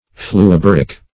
Fluoboric \Flu`o*bo"ric\, a. [Fluo- boric: cf. F. fluoborique.]